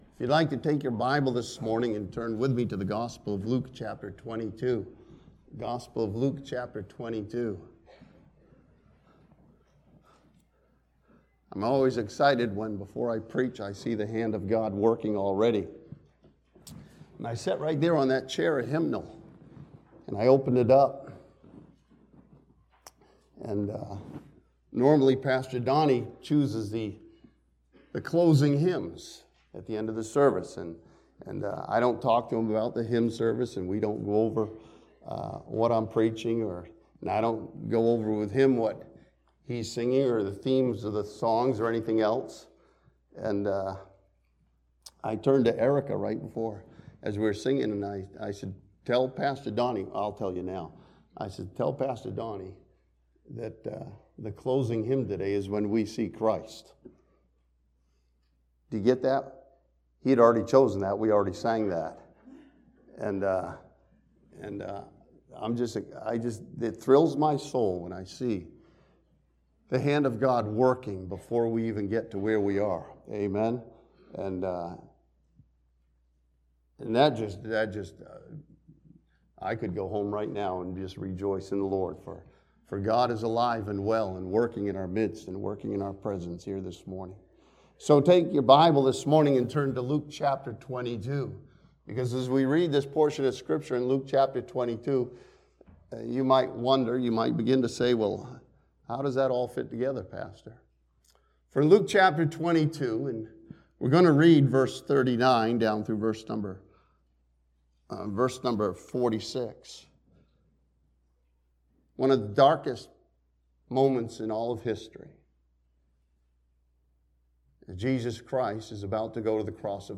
This sermon from Luke chapter 22 challenges believers to view their earthly life with an eternal perspective.